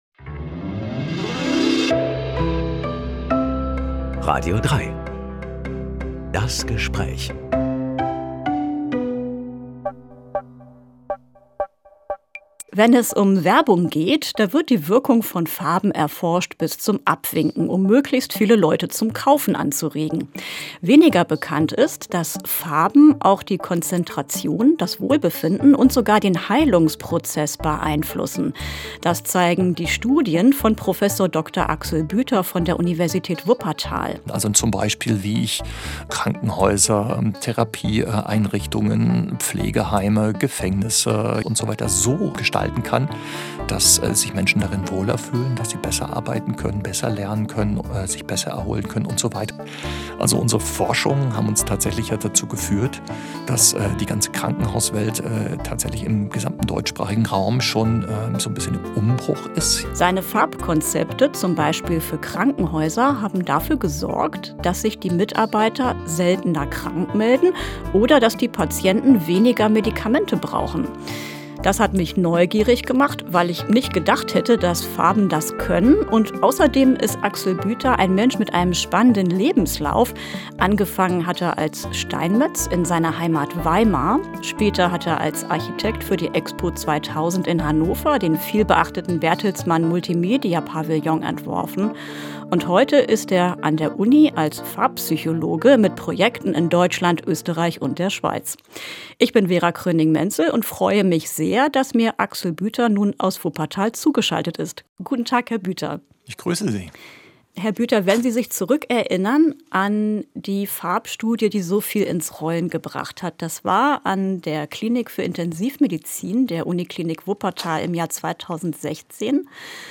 Farbforscher ~ Das Gespräch Podcast